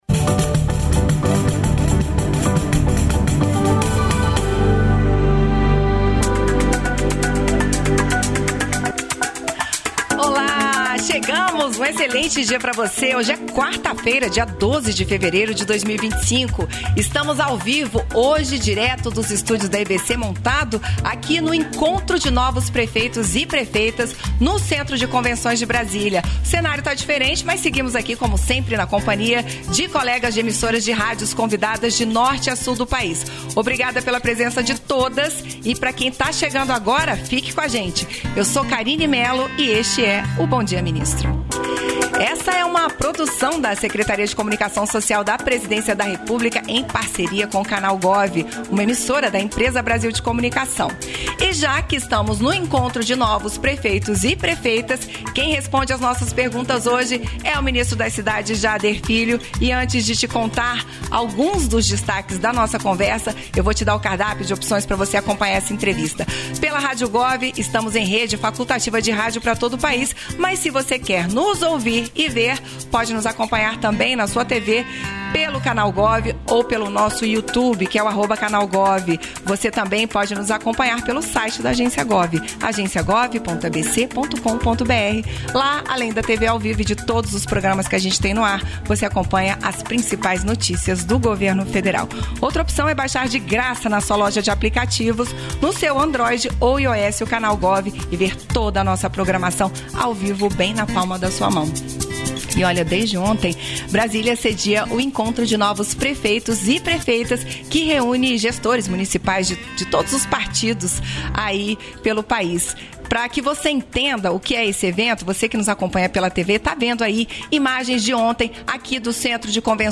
Íntegra da participação do ministro das Cidades, Jader Filho, no programa "Bom Dia, Ministro" desta quarta-feira (12), nos estúdios da EBC no Encontro de Novos Prefeitos e Prefeitas, em Brasília.